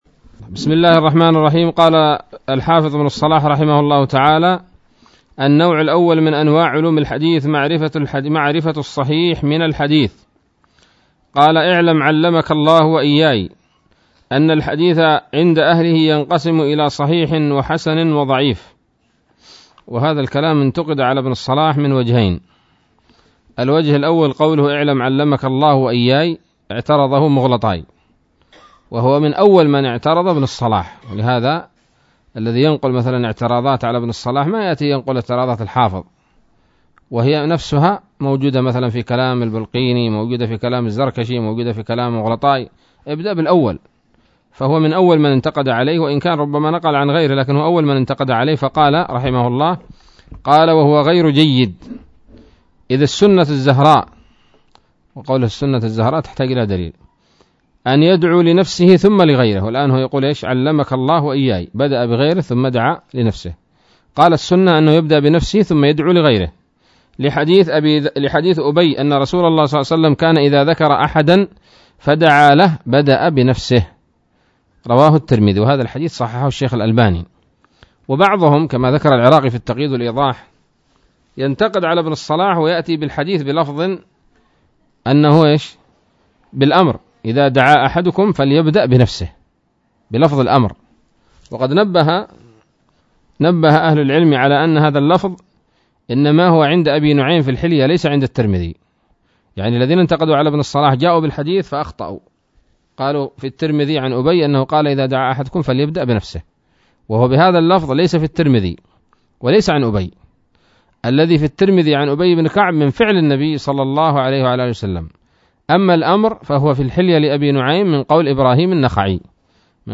الدرس الرابع من مقدمة ابن الصلاح رحمه الله تعالى